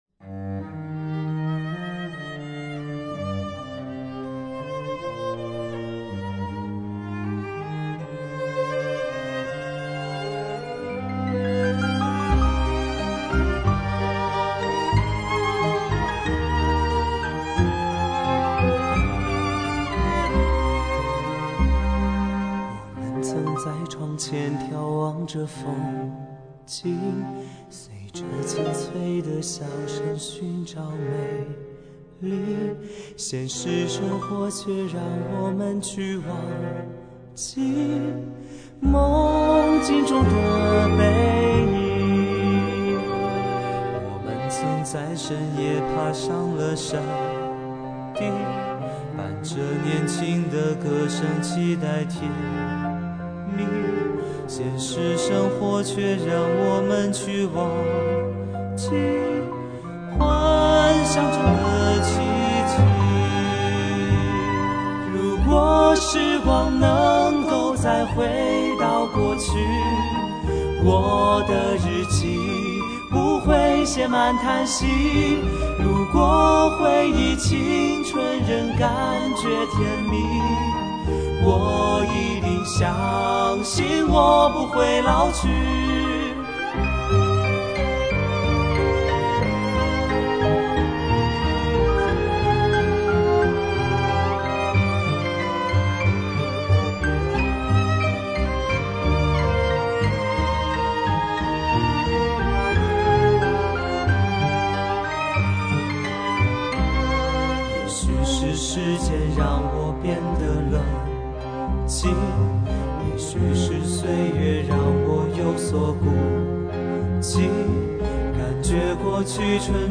第一小提琴
中提琴
大提琴